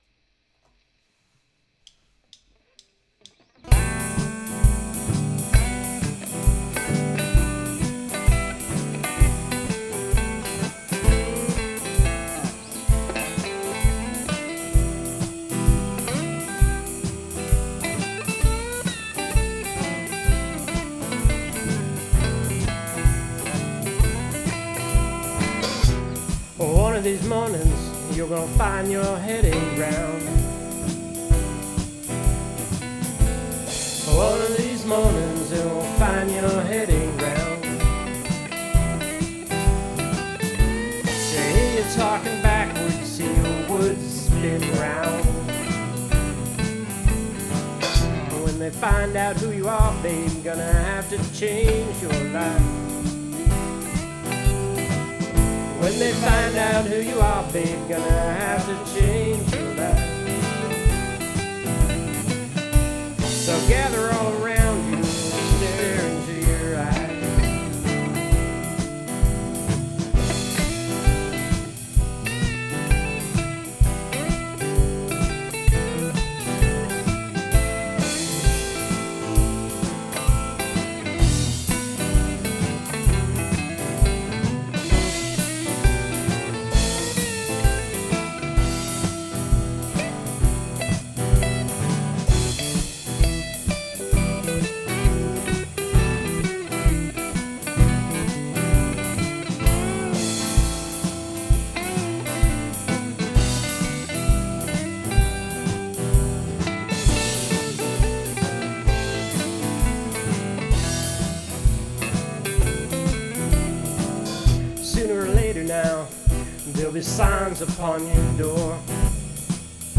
Left Handed Guitar
Bass
Drums
Vocals, Guitars